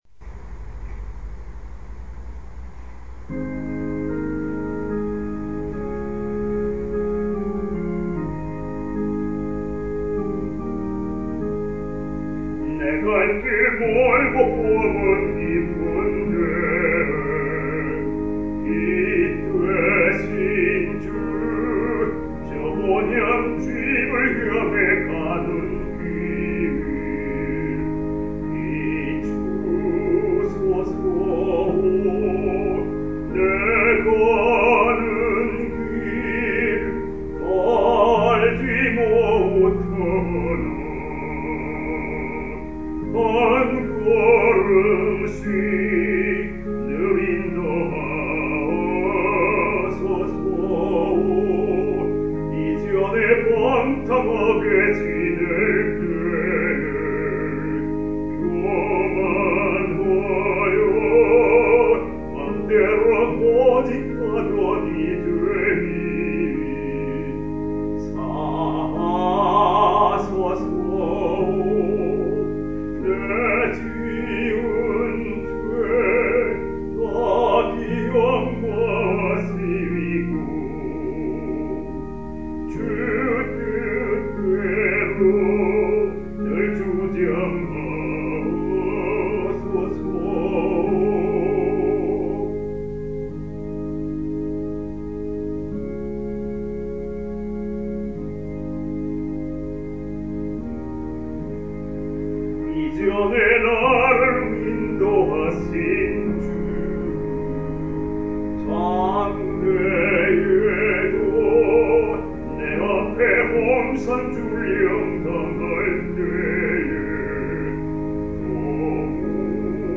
찬송가 (429. 379) 내 갈 길 멀고 밤은 깊은데.